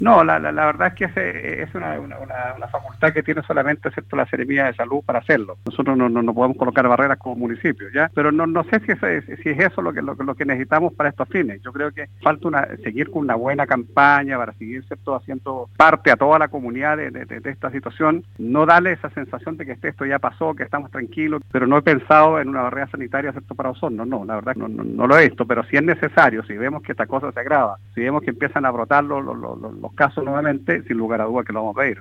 En entrevista con Radio Sago, el alcalde de Osorno, Jaime Bertín, se refirió a la situación actual de la comuna en torno a la evolución de la pandemia, poniendo en duda una eventual normalización de actividades, explicando que quizá la ciudad no está aún preparada para aquello.